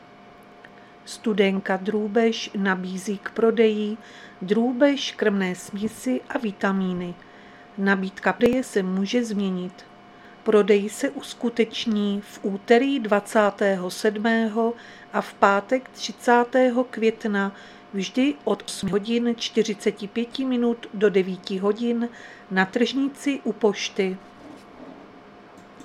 Záznam hlášení místního rozhlasu 26.5.2025